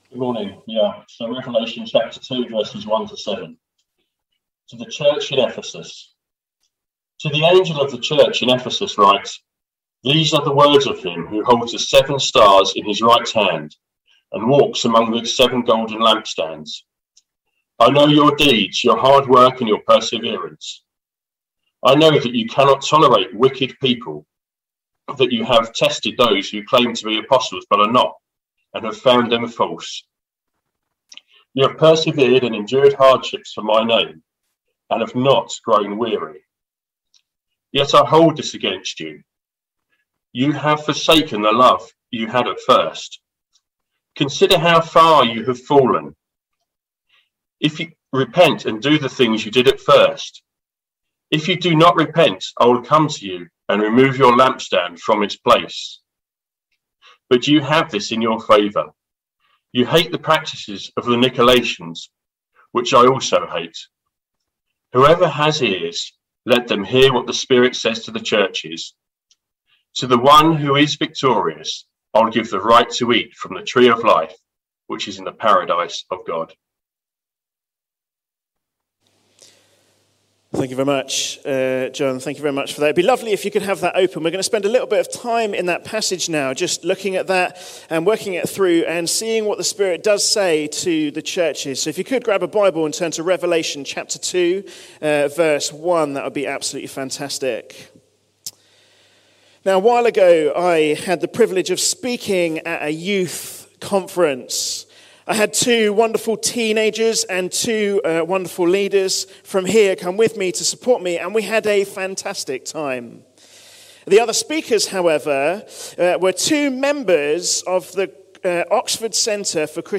A sermon preached on 9th January, 2022.